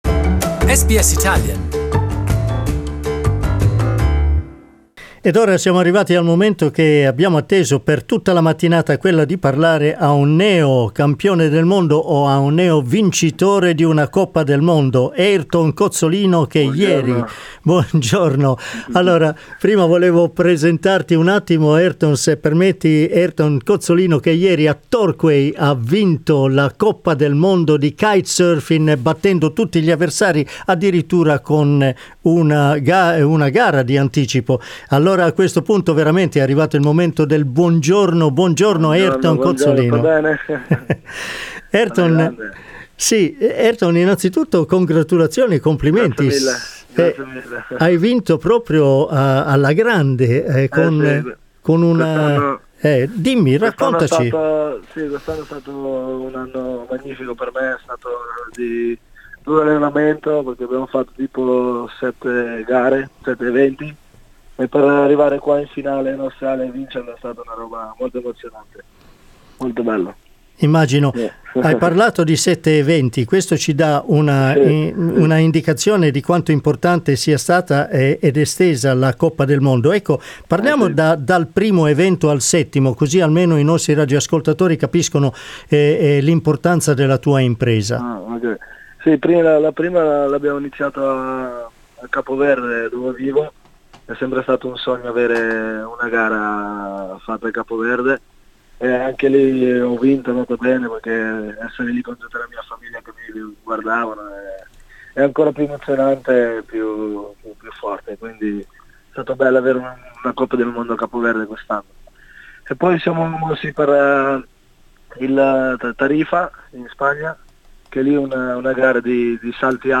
In this "live" interview Airton talks about the World Cup and his personal life.